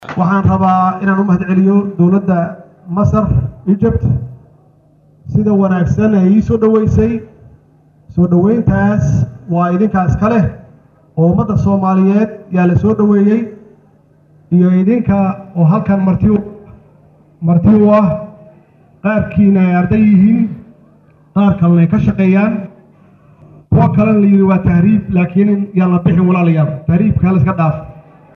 Ardayda iyo sidoo kale Ganacsatadda Soomaaliyeed ee kunool Qaahira ayuu xalay Khudbad dheer u jeediyay isagoona uga warbixiyay Xaaladda Soomaaliya.